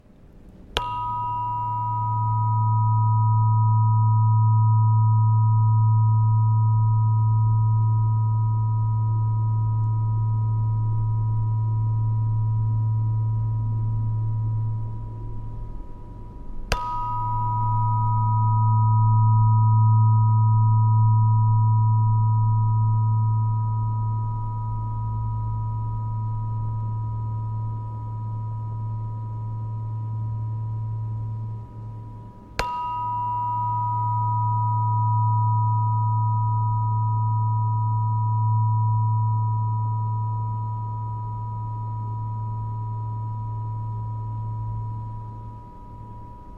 Камертон а1 440 герц